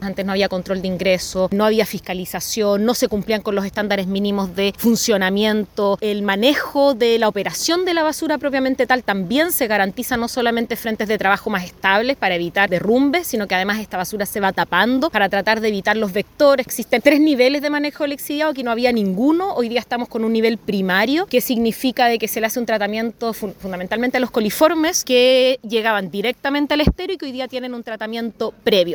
La alcaldesa de Valdivia, Carla Amtmann, indicó que la visita buscó reafirmar que la basura no se puede seguir escondiendo bajo la alfombra.